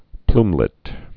(plmlĭt)